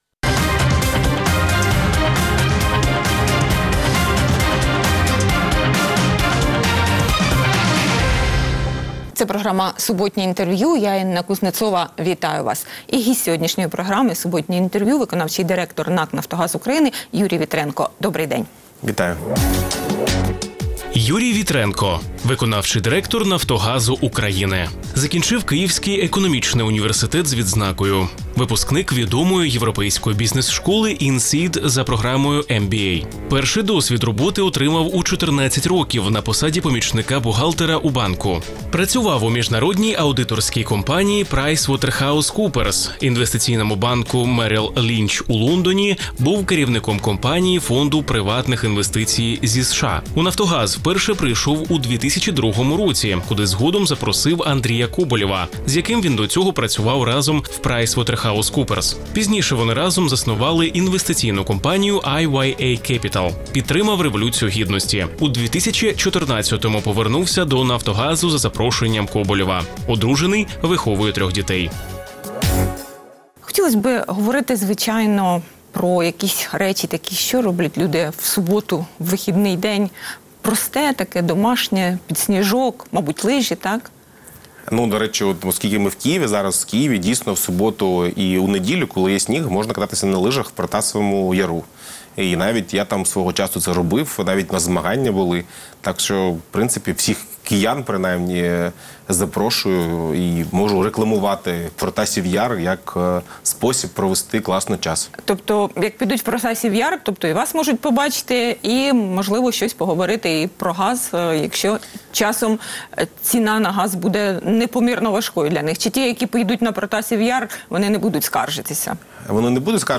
Суботнє інтерв’ю | Юрій Вітренко, виконавчий директор НАК «Нафтогаз України»
Суботнє інтвер’ю - розмова про актуальні проблеми тижня. Гість відповідає, в першу чергу, на запитання друзів Радіо Свобода у Фейсбуці